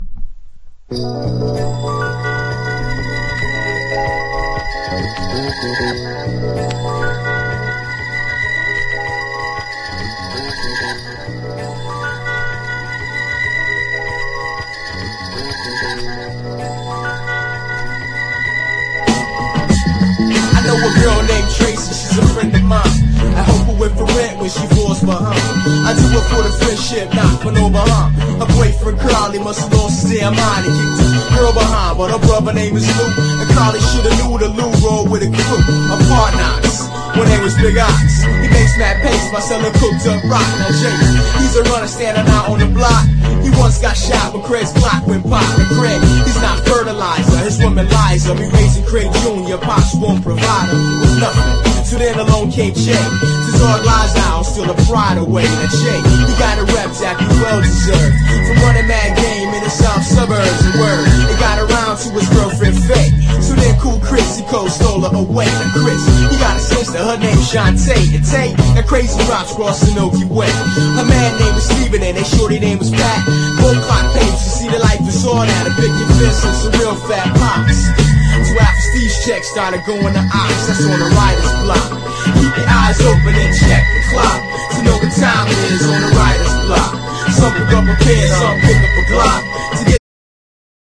90’S HIPHOP# JAZZY HIPHOP# UNDERGROUND HIPHOP